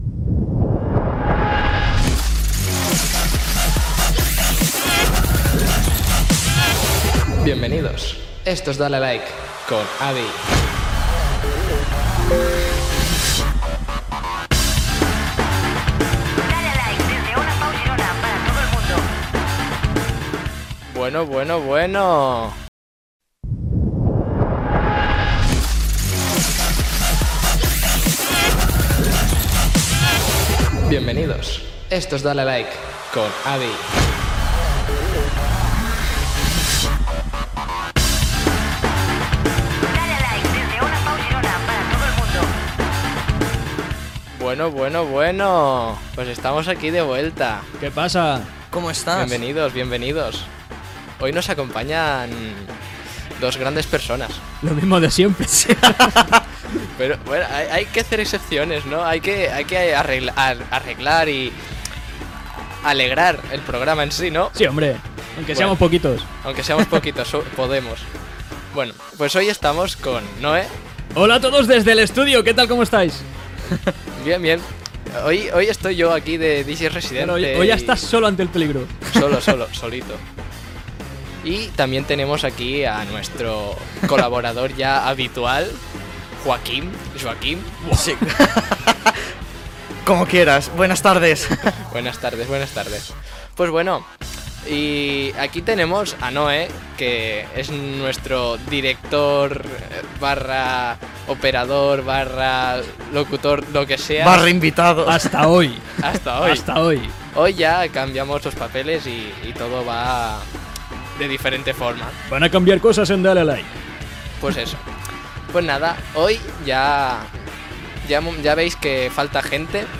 Careta del programa, presentació de l'equip, xarxes socials del programa, sumari de continguts, tema musical
FM